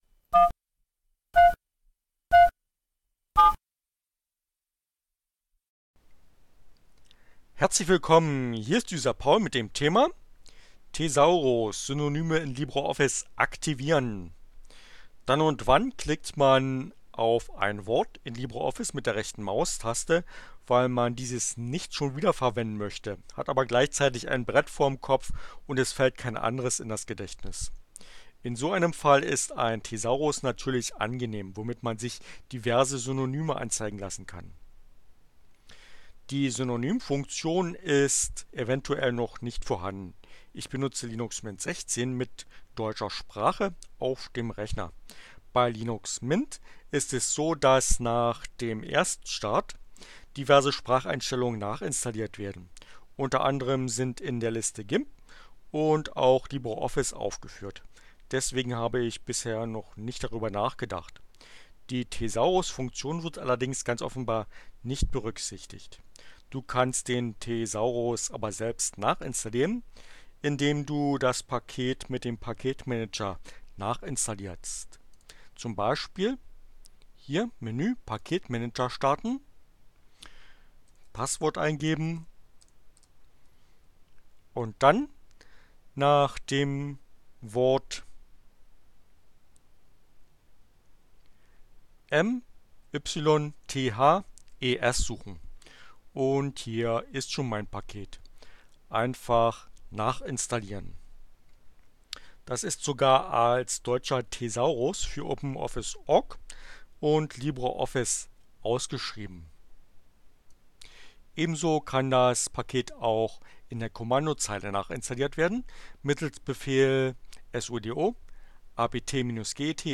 Tags: CC by, Linux, Neueinsteiger, ohne Musik, screencast, LibreOffice